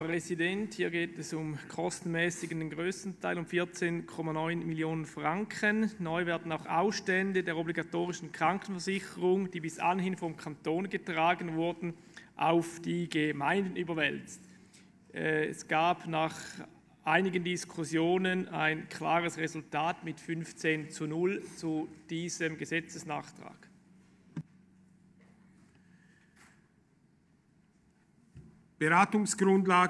Kommissionspräsident: Auf die Vorlage ist einzutreten.
Session des Kantonsrates vom 18. bis 20. Mai 2020, Aufräumsession